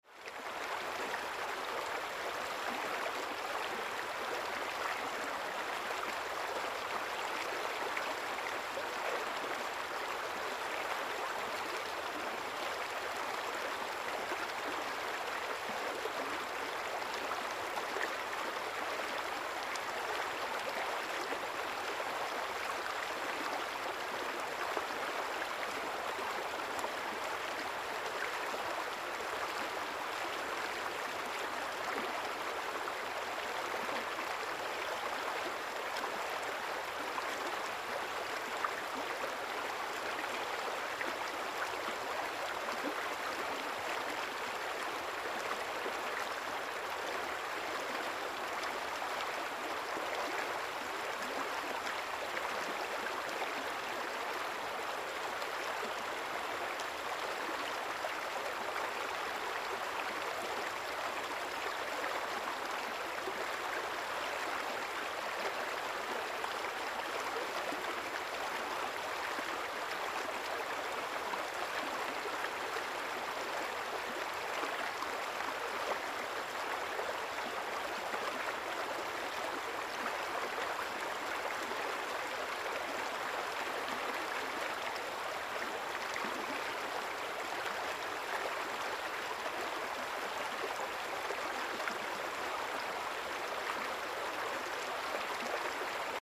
Звуки реки
На этой странице собраны натуральные звуки рек и ручьев в высоком качестве.